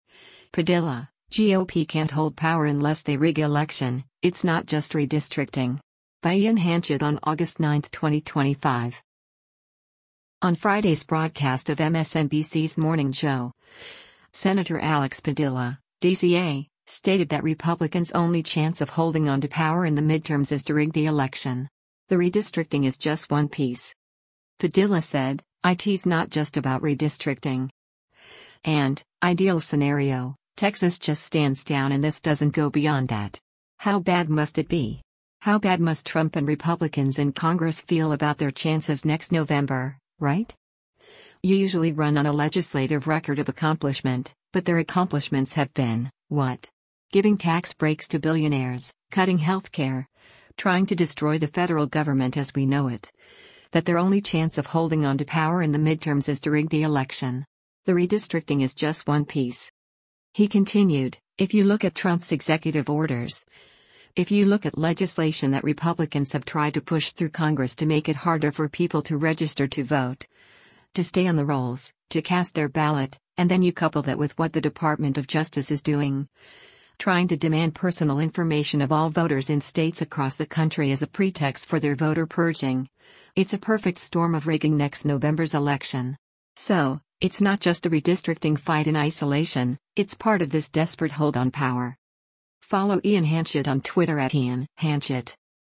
On Friday’s broadcast of MSNBC’s “Morning Joe,” Sen. Alex Padilla (D-CA) stated that Republicans’ “only chance of holding on to power in the midterms is to rig the election. The redistricting is just one piece.”